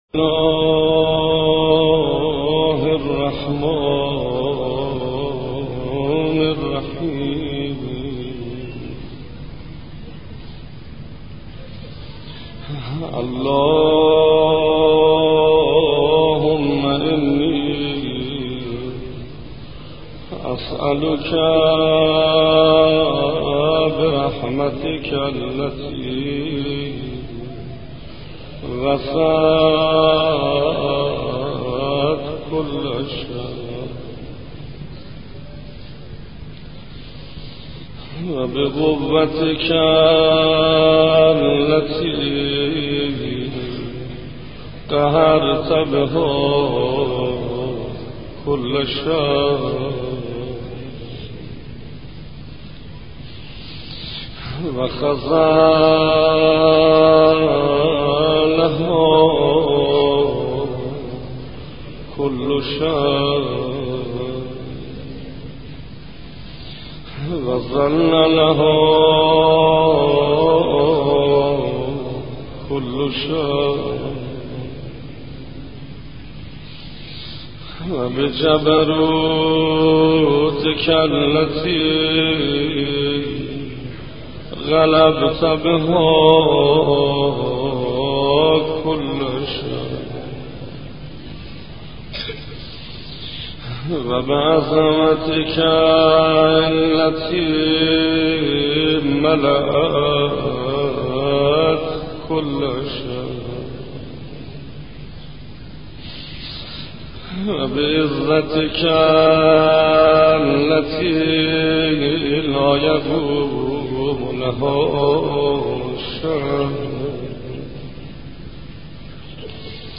قرائت دعای افتتاح- شیخ حسین انصاریان